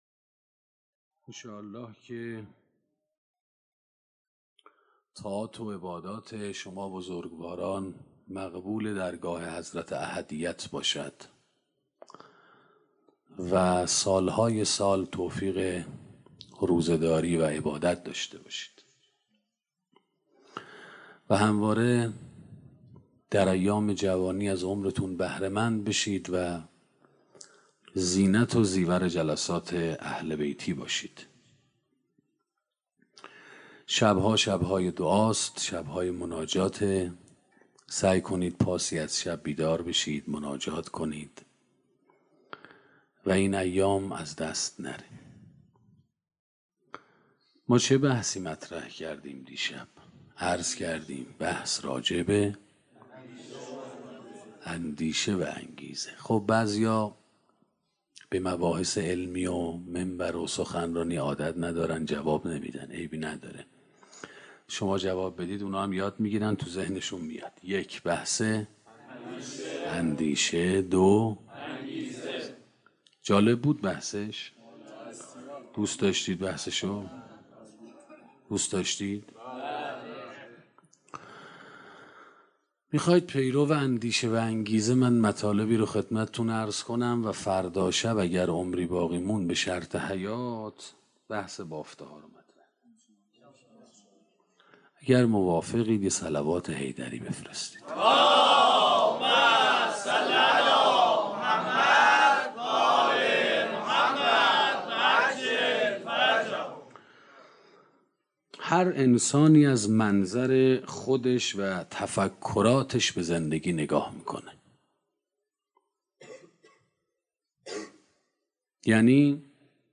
سخنرانی اندیشه و انگیزه 2 - موسسه مودت